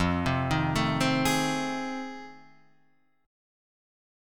F7#9 Chord